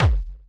drum43.mp3